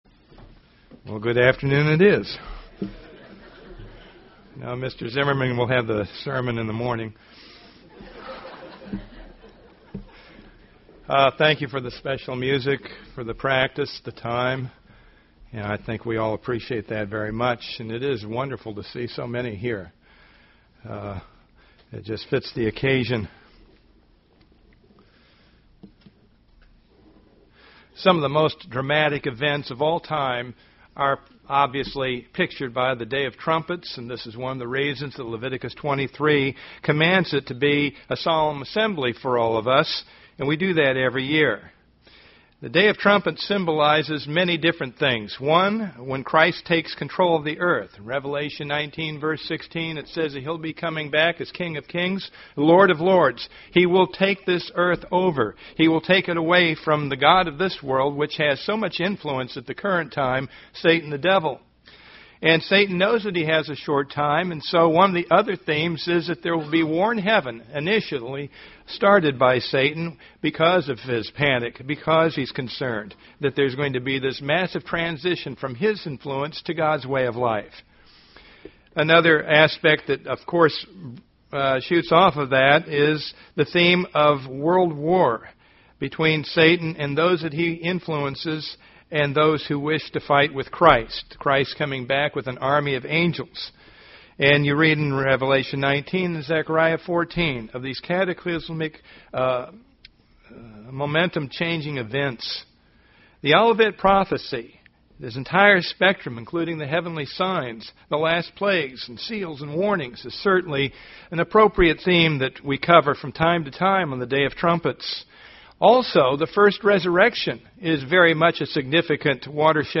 Given in Tampa, FL
UCG Sermon Studying the bible?